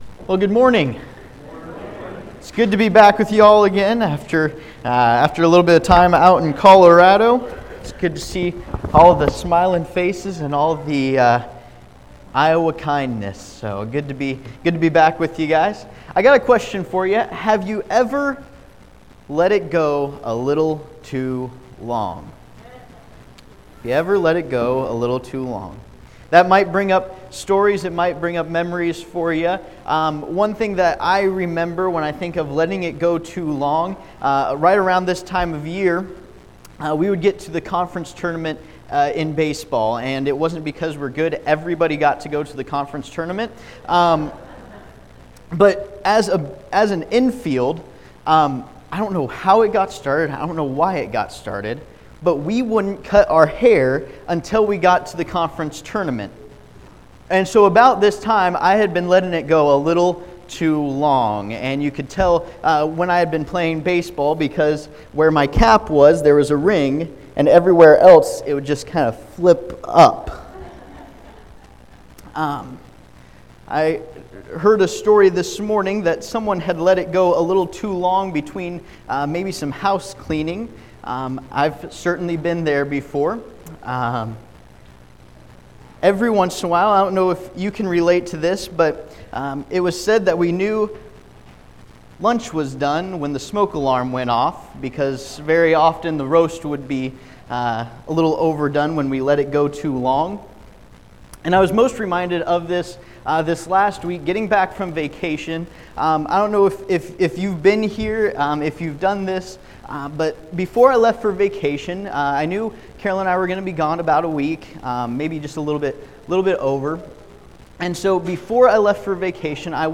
2Chronicles 7:14 Service Type: Sunday Morning « Future Planning We’ve Got to Move It!